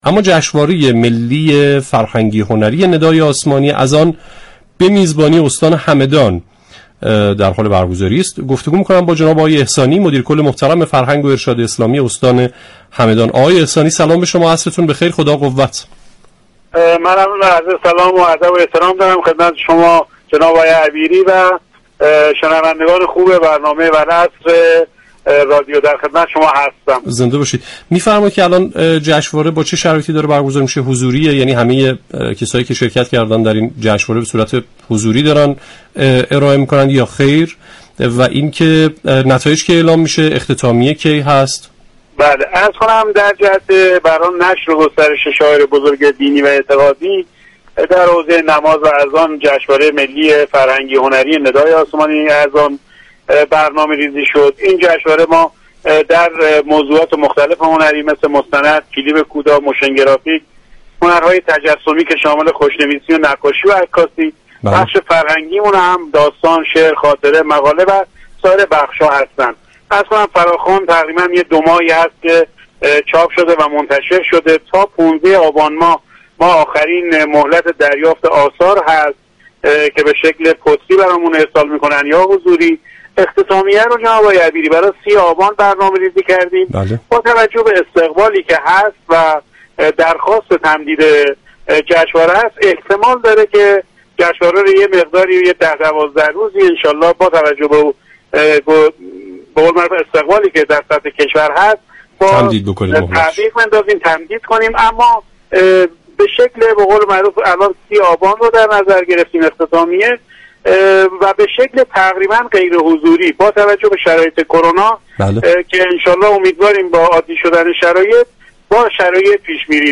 احمدرضا احسانی مدیركل فرهنگ و ارشاد اسلامی استان همدان در گفتگو با برنامه والعصر 5 آبان ماه از انتشار فراخوان جشنواره ملی فرهنگی و هنری ندای آسمانی خبر داد .